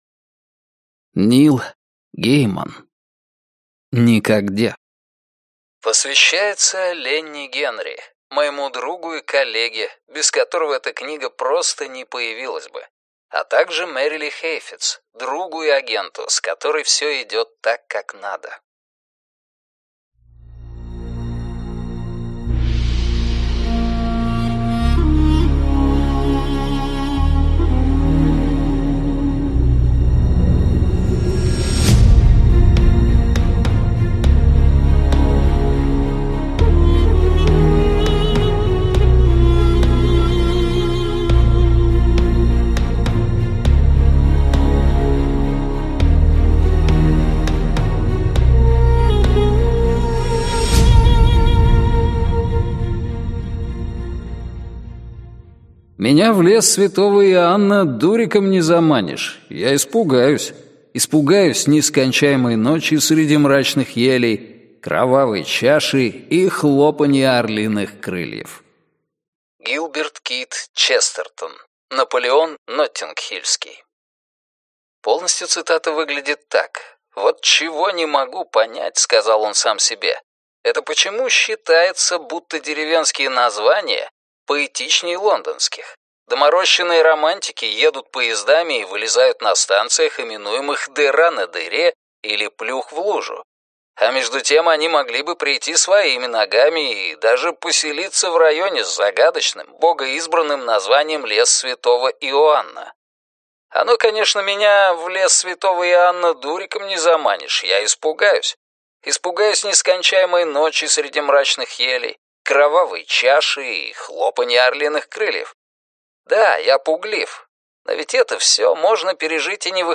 Аудиокнига Никогде - купить, скачать и слушать онлайн | КнигоПоиск